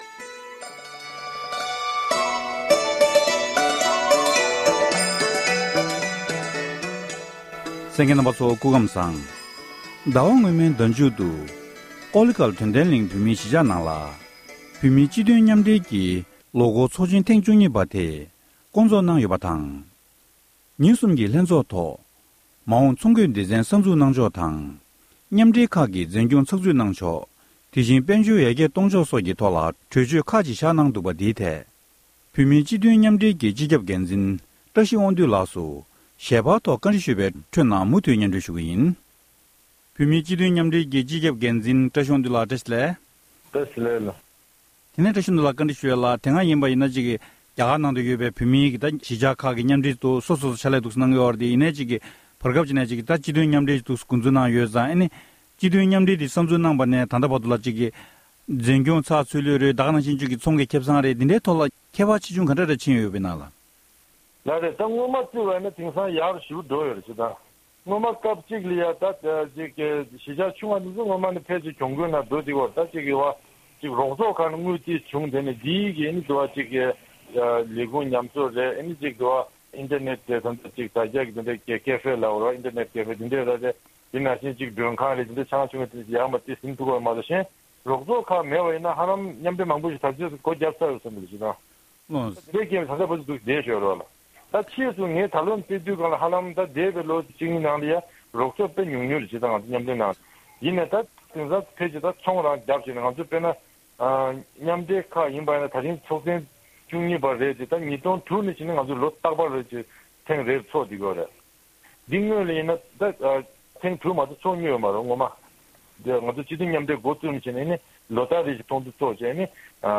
གནས་འདྲི་ཞུས་པར་མུ་མཐུད་ནས་གསན་རོགས༎